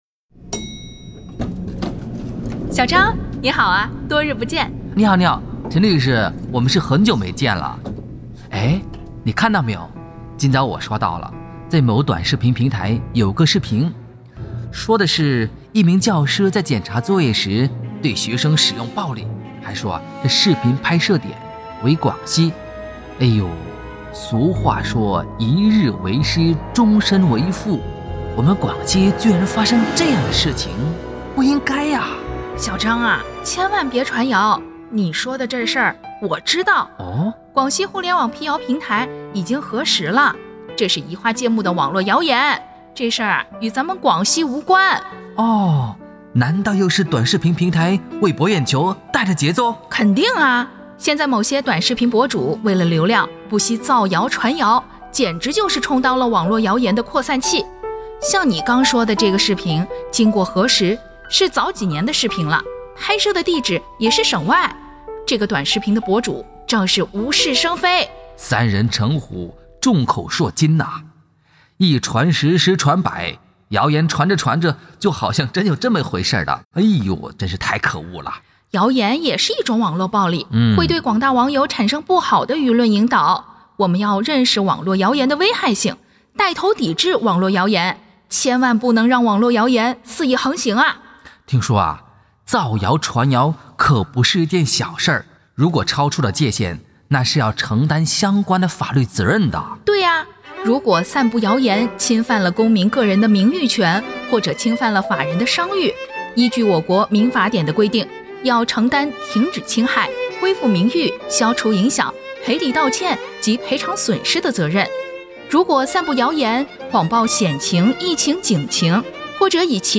作品借助小张和陈律师的对话形式，深入剖析了网络谣言的传播机制和法律责任，强调了抵制网络谣言的重要性。